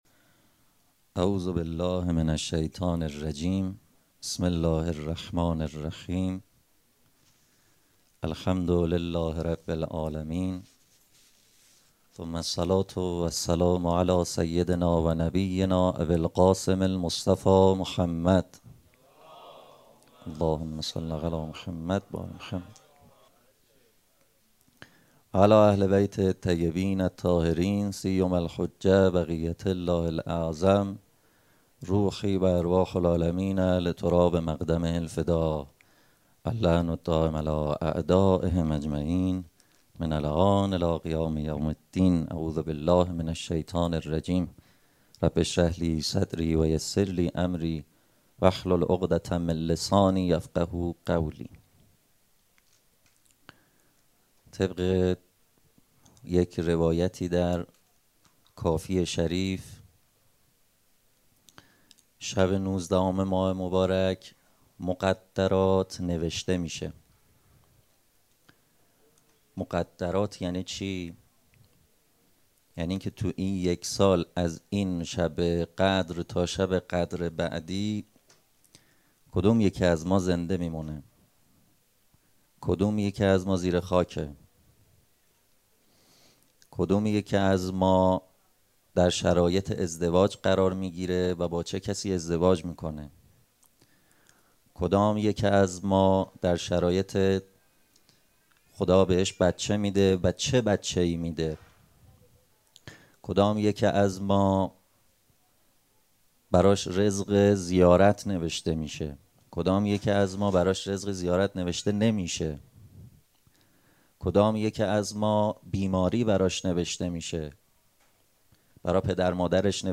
سخنرانی شب 21 رمضان97
حسینیه بنی فاطمه(س)بیت الشهدا